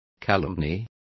Complete with pronunciation of the translation of calumny.